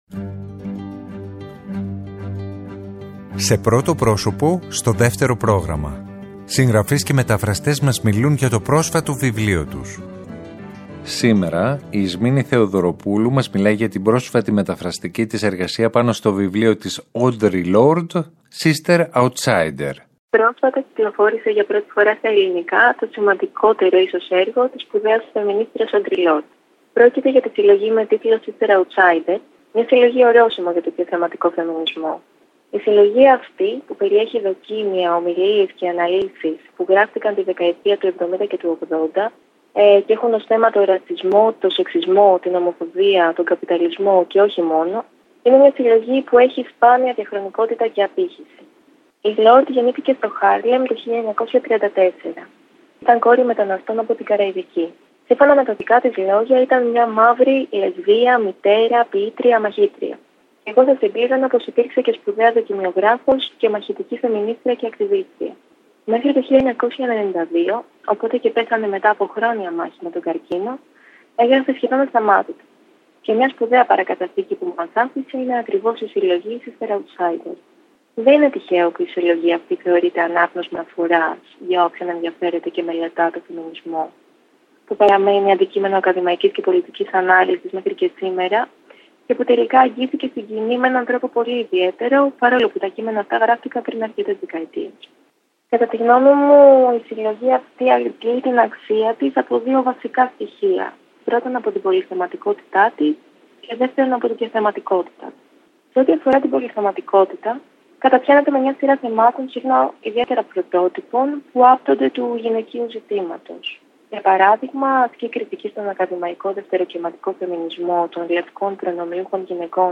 Συγγραφείς και μεταφραστές μιλάνε